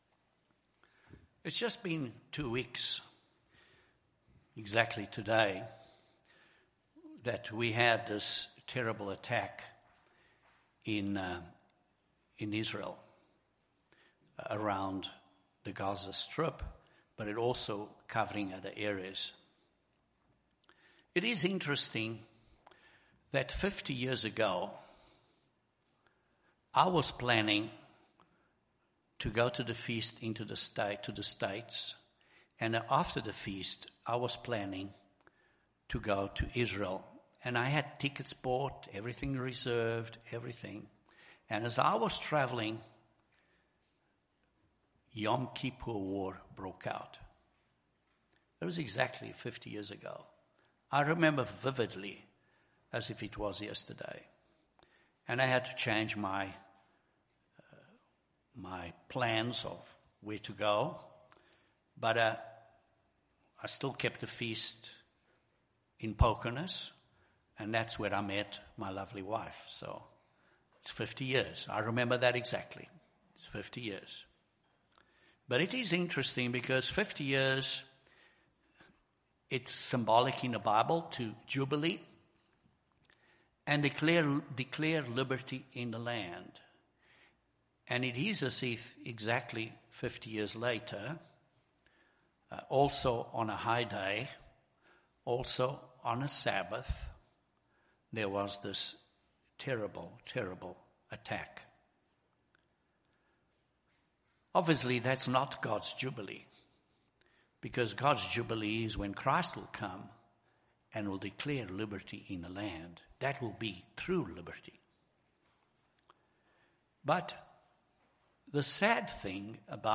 This sermon discusses a few key steps that we need to take so that we will be able to stand at the coming of the Son of Man.